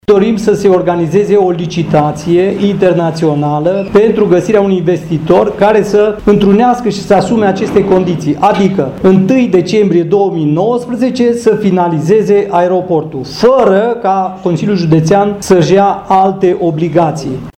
Concret, președintele Comisiei pentru Aeroport, din CJ Brașov, Ionel Spinean, susține că a identificat un investitor din Turcia, dispus să ofere și o garanție bancară și care să realizeze investiția în doi ani.